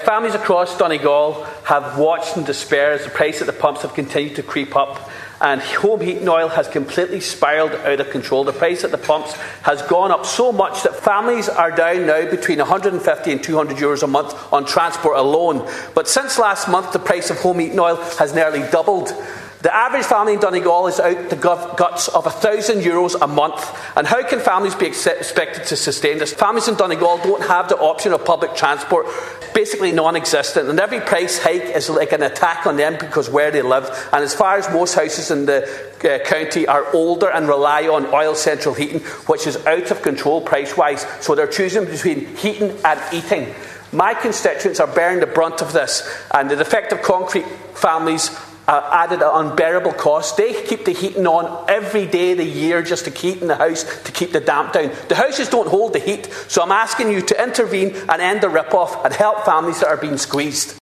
A Donegal TD has told the Dail that families in the county are bearing the brunt of rising energy and fuel costs.